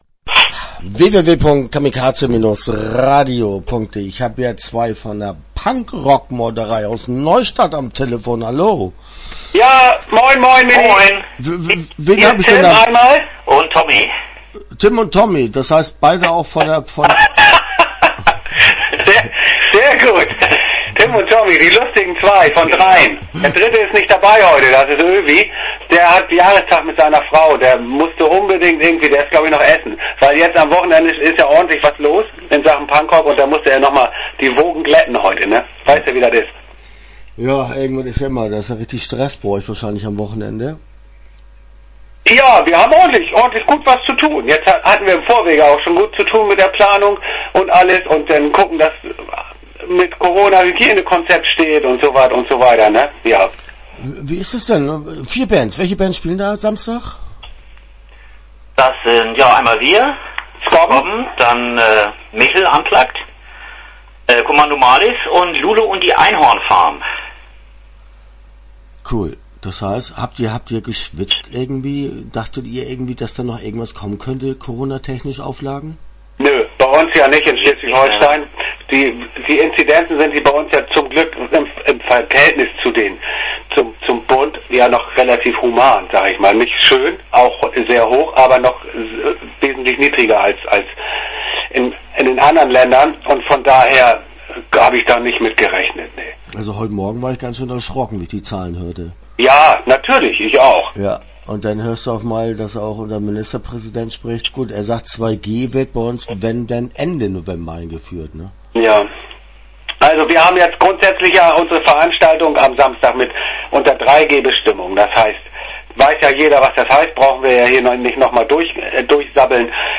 Start » Interviews » PunkRock Meuterei